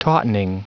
Prononciation du mot tautening en anglais (fichier audio)
Prononciation du mot : tautening